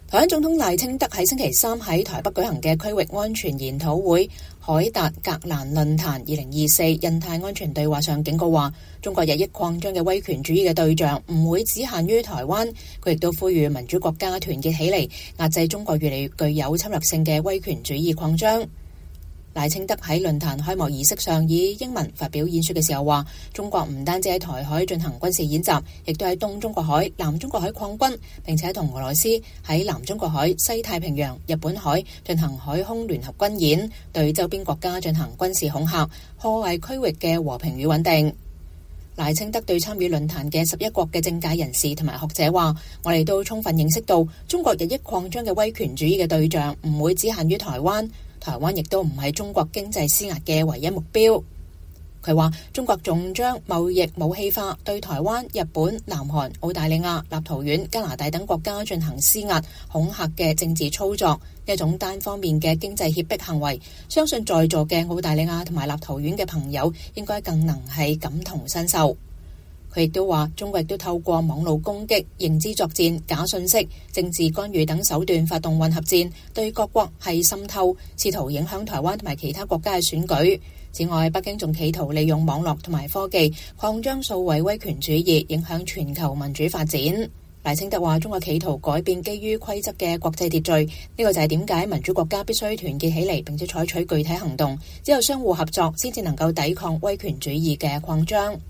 台灣總統賴清德星期三(8月21日)在台北舉行的的區域安全研討會「凱達格蘭論壇-2024印太安全對話」上警告說，中國日益擴張的威權主義的對像不會僅限於台灣。他也呼籲民主國家團結起來遏制中國越來越具有侵略性的威權主義擴張。